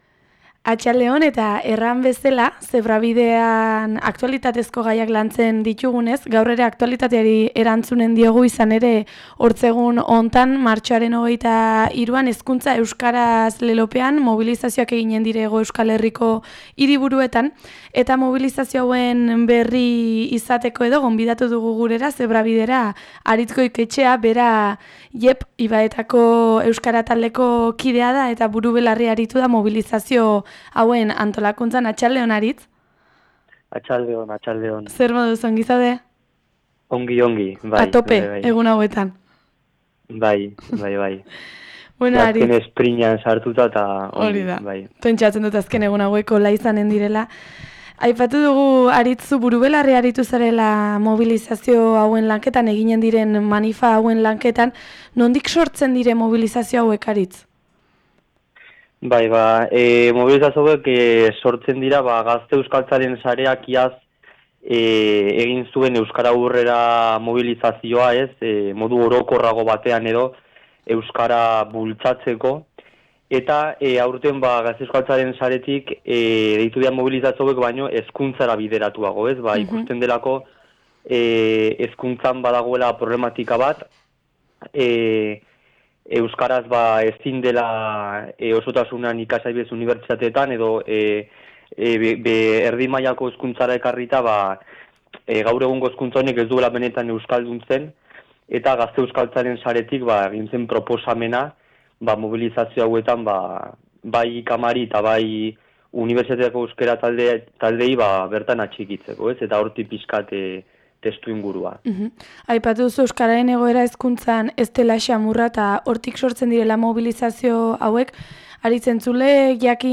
Eguneko elkarrizketa